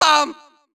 baBumBumBum_FarAlt4.wav